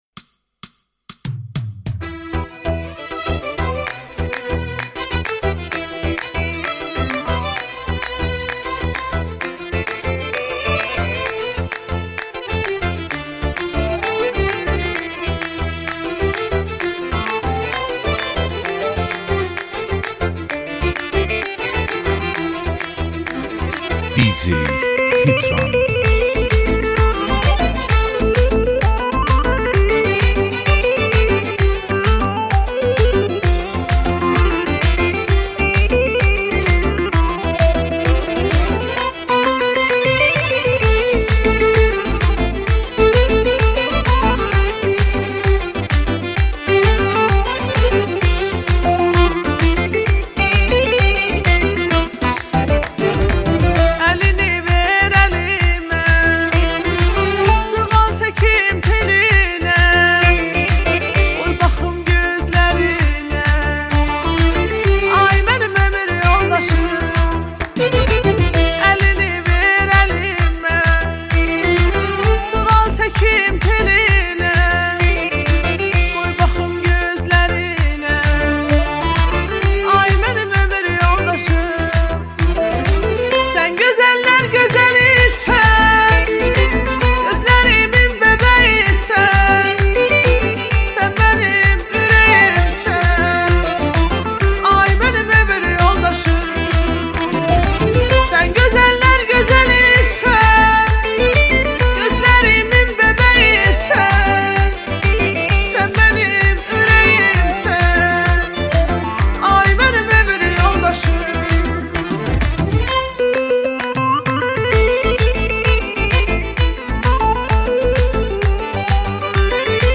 دانلود آهنگ ترکی